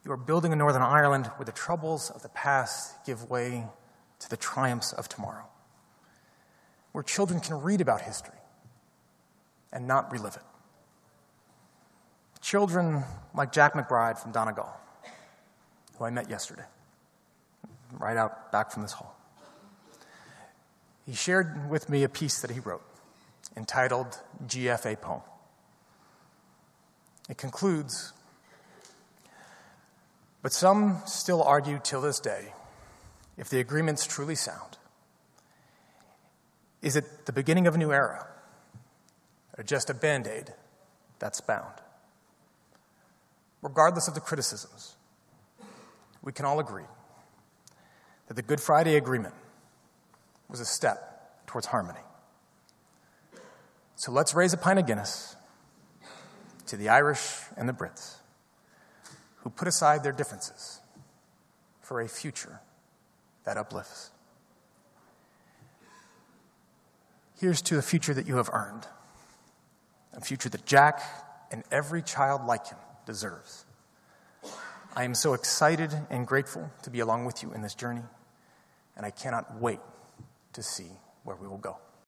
That’s an extract from a poem penned by a Donegal schoolboy which was recited by the US Special Envoy to Northern Ireland at a conference at Queen’s University Belfast today to mark the 25th anniversary of the Good Friday Agreement.
Today, Mr Kennedy spoke of a future that the people of Northern Ireland have earned through their strive towards peace: